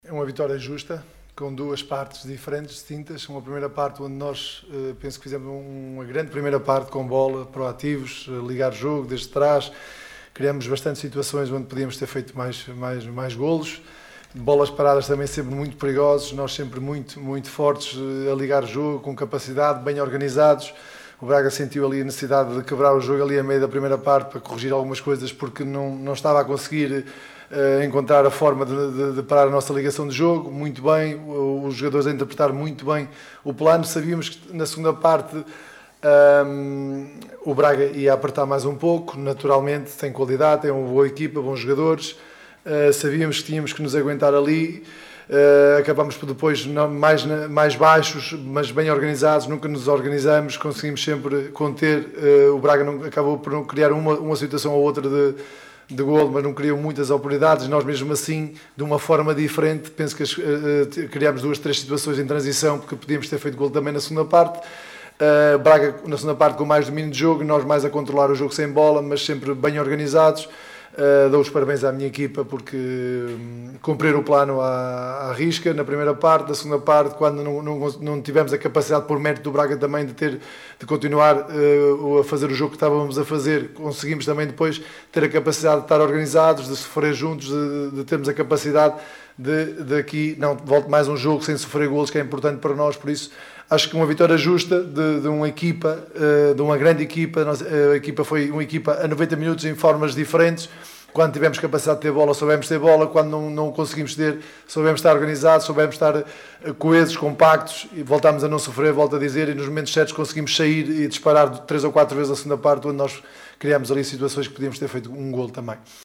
No final do desafio, Cesar Peixoto, treinador gilista, falou de uma vitória justa.